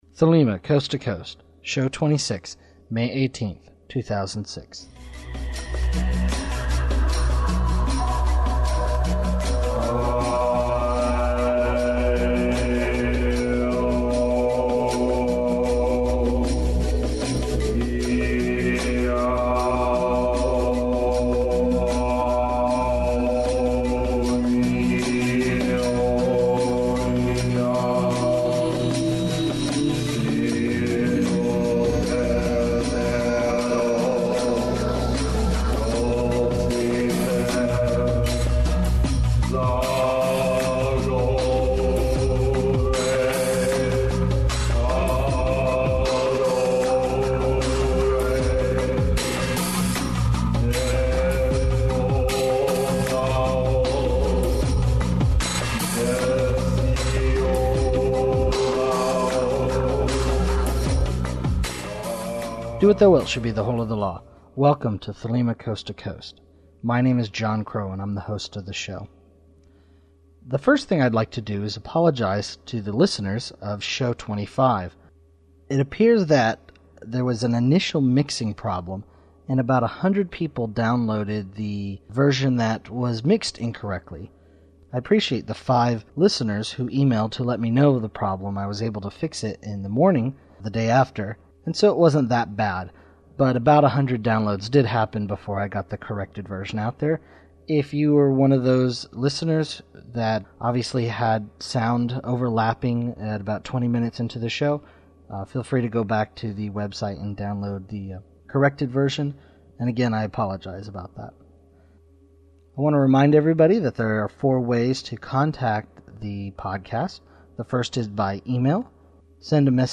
Opening music
Listener Feedback
Interview